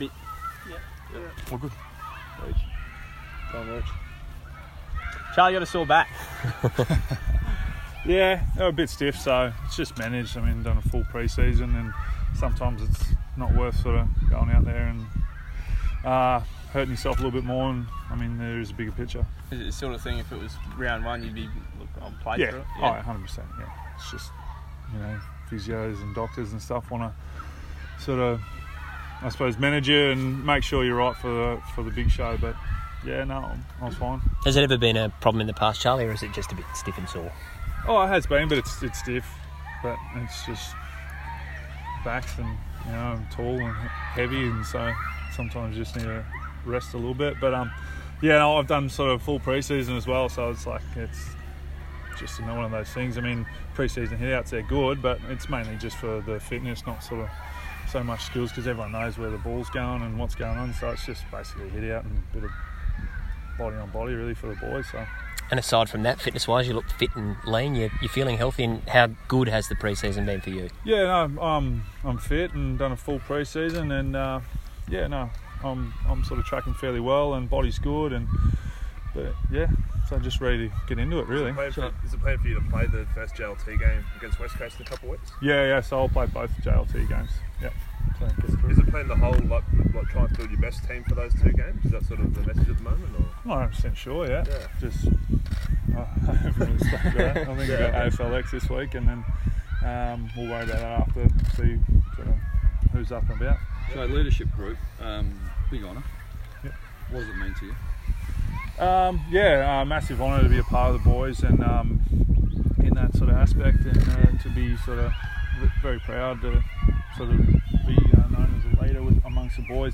Charlie Dixon press conference - Monday, 12 February, 2018
Charlie Dixon talks with media at Port Broughton Area School as Port Adelaide begins its 2018 Community Camp.